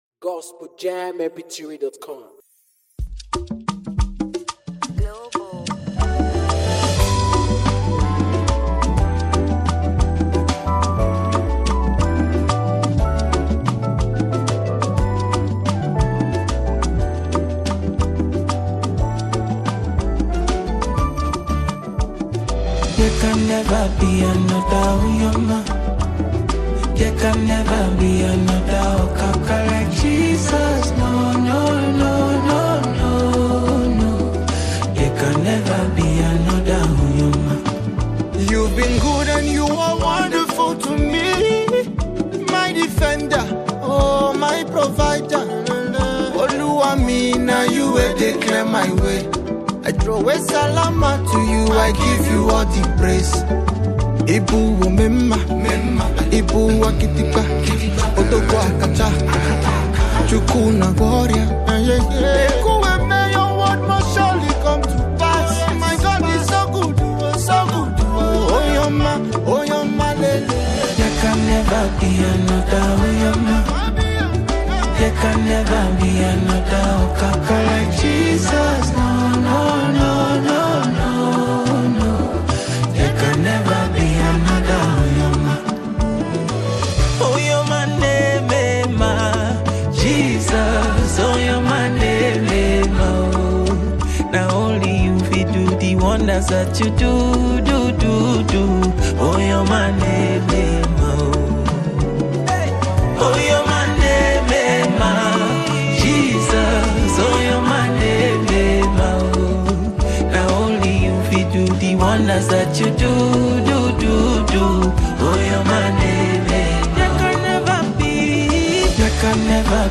Afro beatmusic